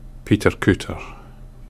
Peterculter /ˌptərˈktər/
Peterculter.ogg.mp3